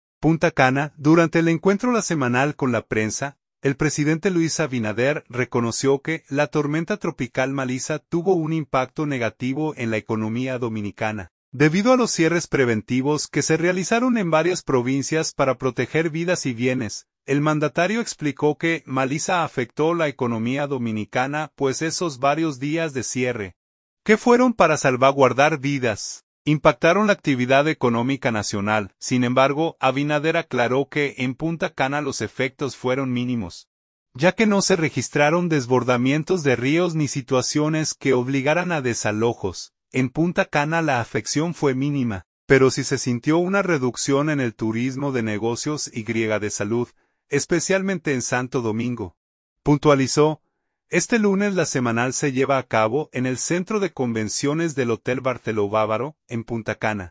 Punta Cana.– Durante el encuentro LA Semanal con la prensa, el presidente Luis Abinader reconoció que la tormenta tropical Melissa tuvo un impacto negativo en la economía dominicana, debido a los cierres preventivos que se realizaron en varias provincias para proteger vidas y bienes.
Este lunes La Semanal se lleva a cabo en el Centro de Convenciones del Hotel Barceló Bávaro, en Punta Cana.